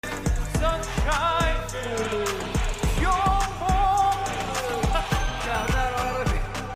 it's sounds like opera 🤣😂 sound effects free download
You Just Search Sound Effects And Download. tiktok hahaha sound effect Download Sound Effect Home